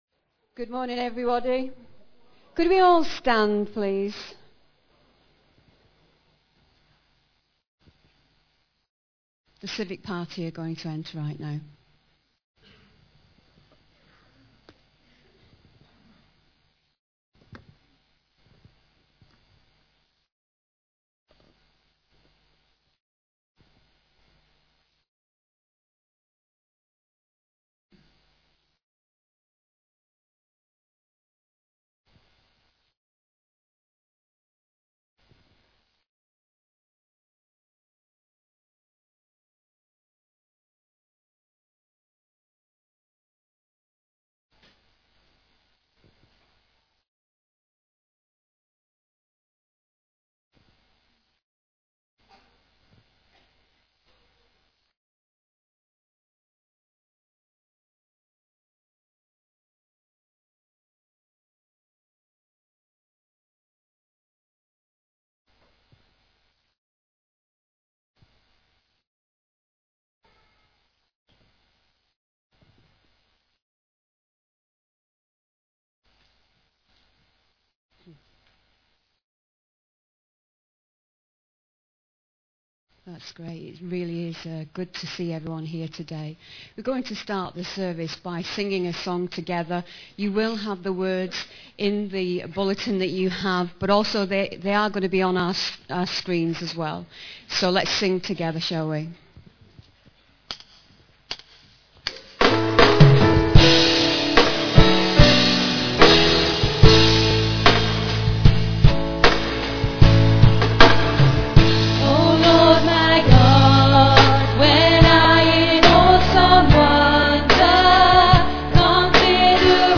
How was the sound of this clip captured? Civic Service.mp3